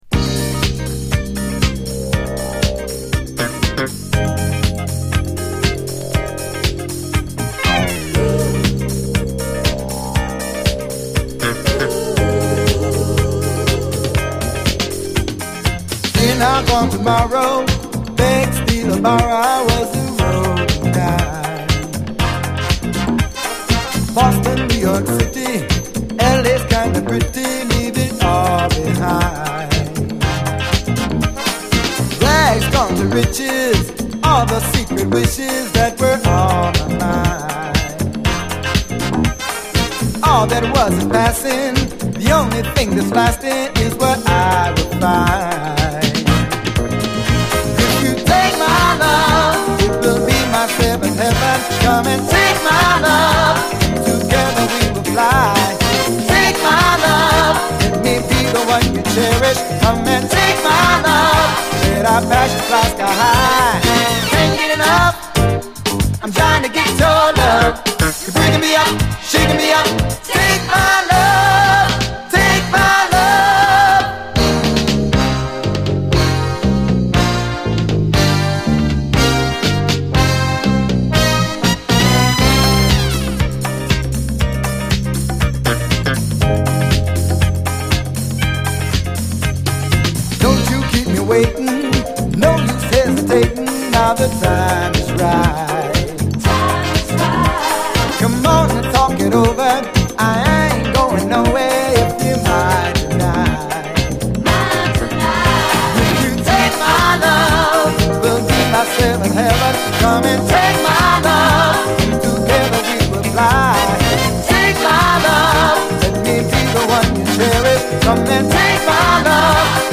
SOUL, 70's～ SOUL
軽快なギター・カッティングのトロピカル・モダン・ソウル・ダンサー